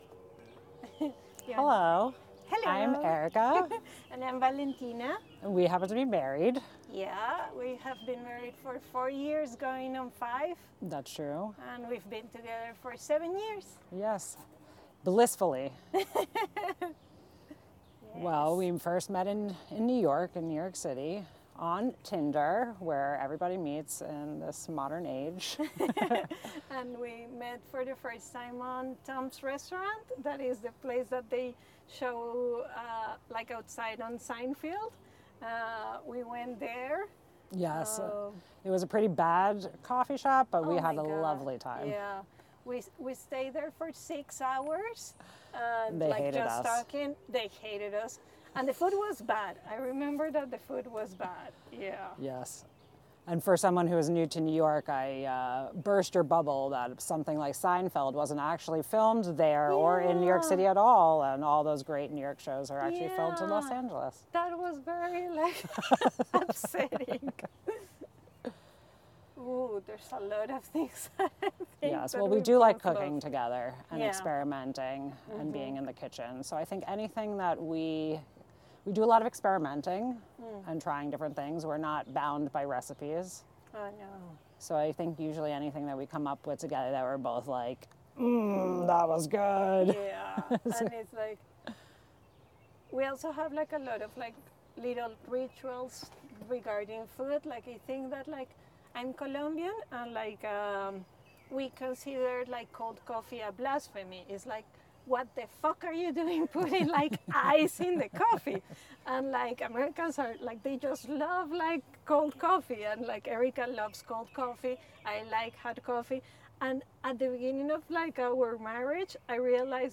A link to the audio files recorded by the couples is also available at the table, where you are invited to sit, listen, and reminisce about your own love stories and food memories.